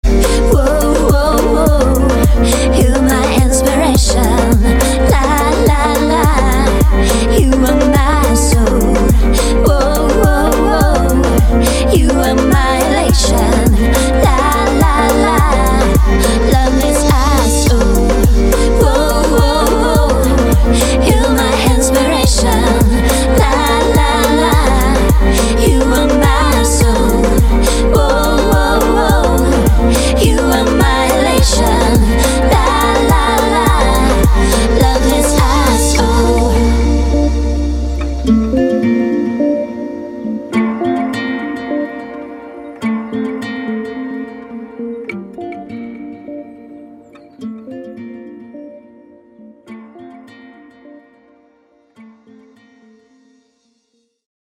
• Качество: 160, Stereo
поп
гитара
ритмичные
громкие
dance
красивая мелодия
чувственные